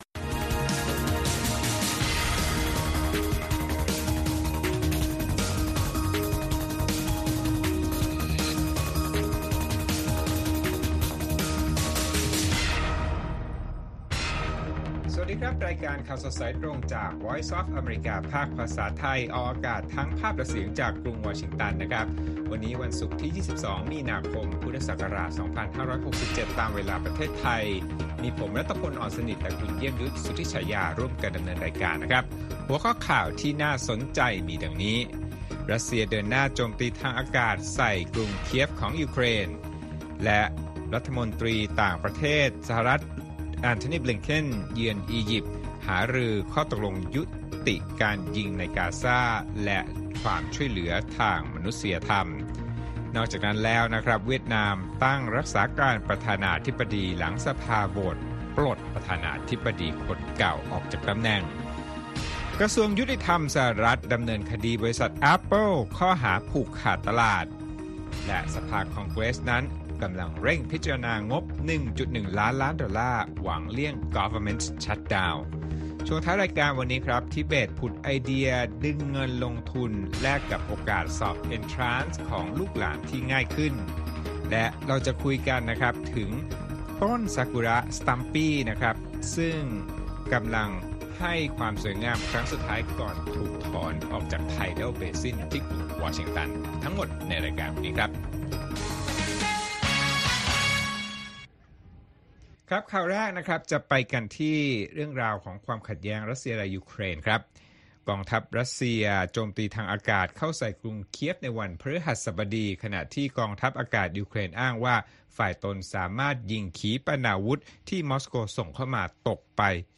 ข่าวสดสายตรงจากวีโอเอไทย วันศุกร์ ที่ 22 มี.ค. 2567